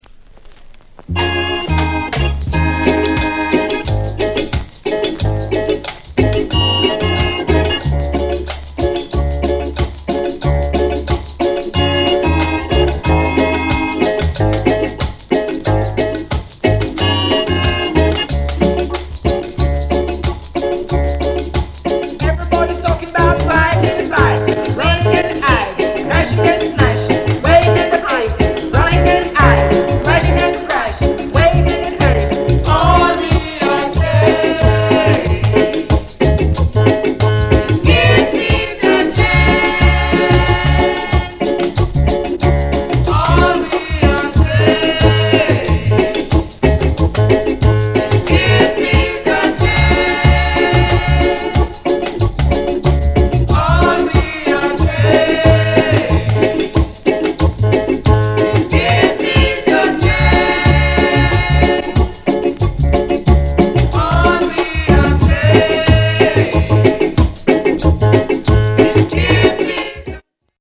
RARE EARLY REGGAE INST